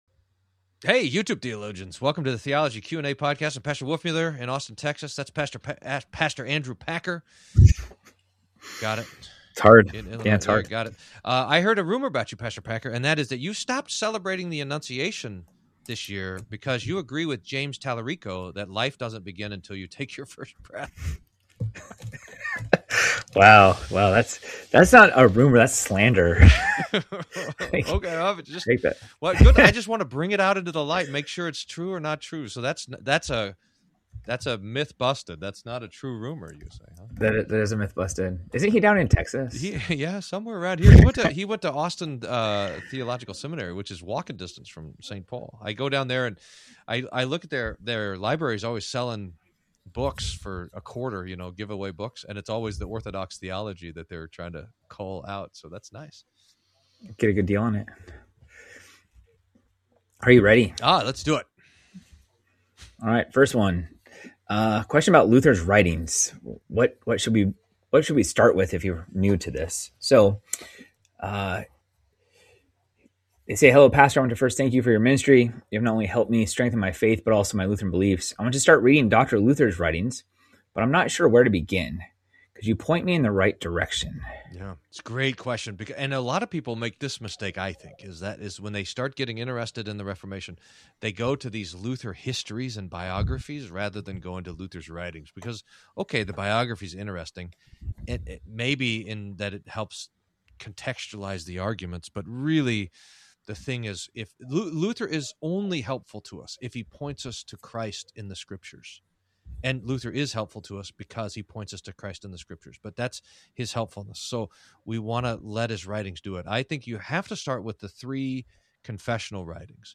Q&A: Luther writings for beginners? Is the Catholic Church the only true church? Can Christians start revolutions? More.